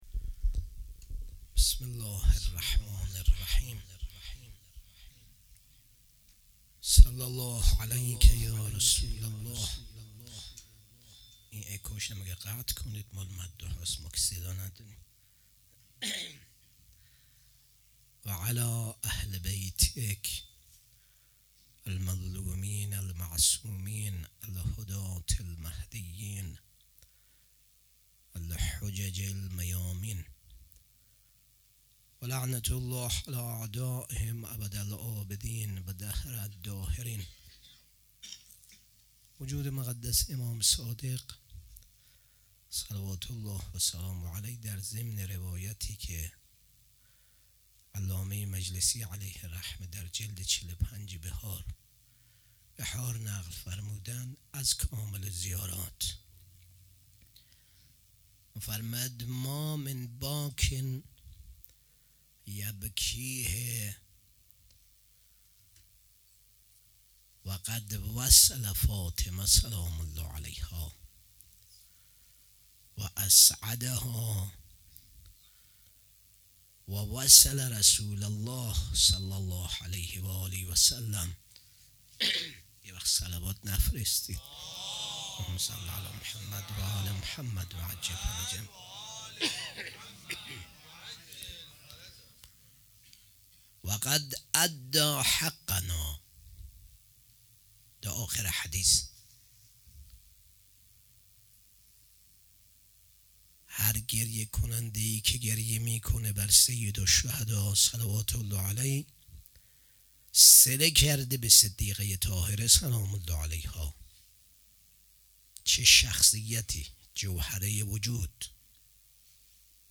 شب ششم محرم96 - سخنرانی